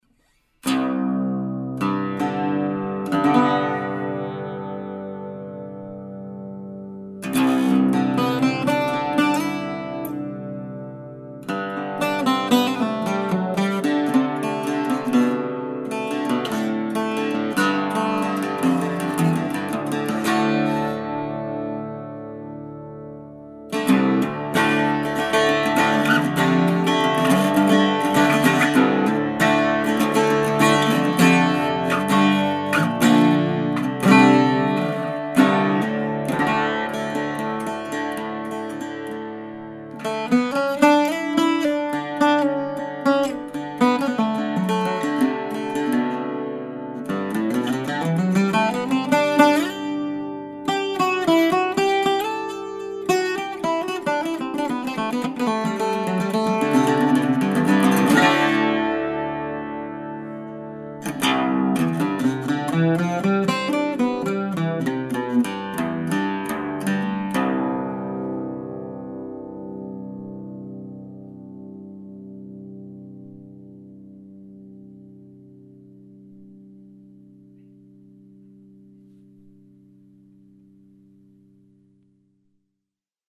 SOUND CLIPS - MANDOLIN